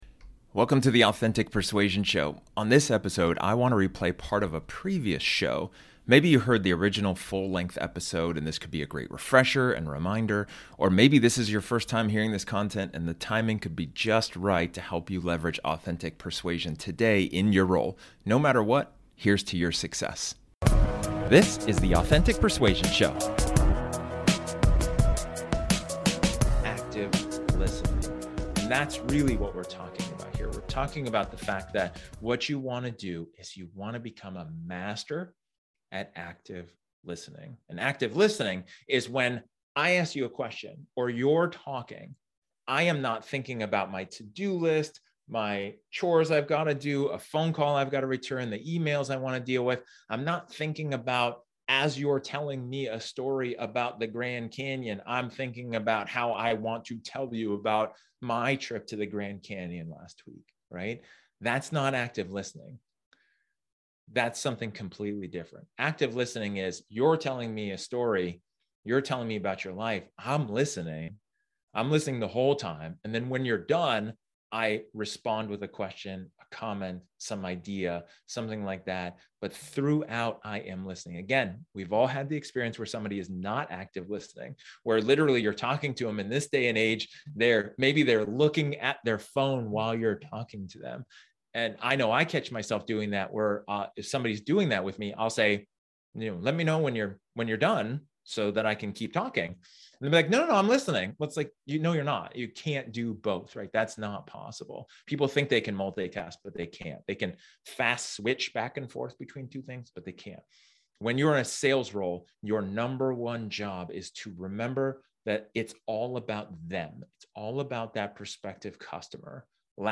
This episode is an excerpt from one of my training sessions where I talk about building rapport.